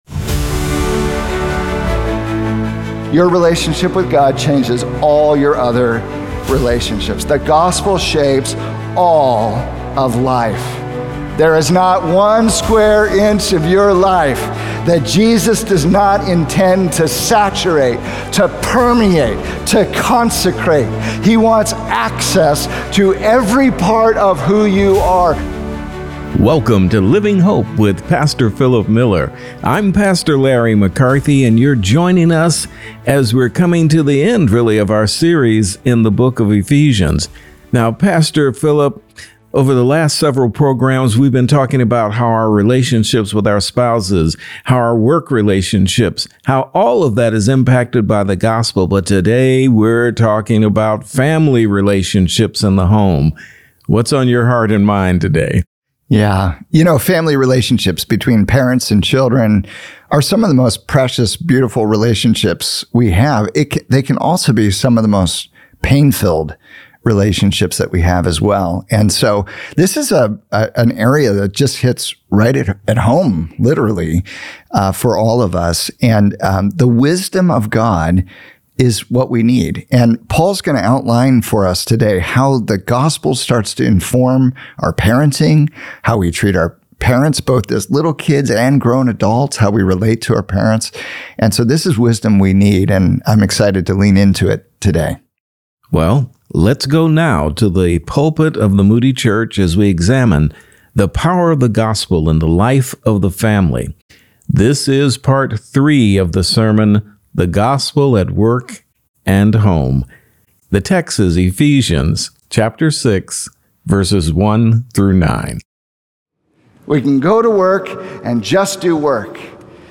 Nurturing Your Family Without Crushing Their Spirits | Radio Programs | Living Hope | Moody Church Media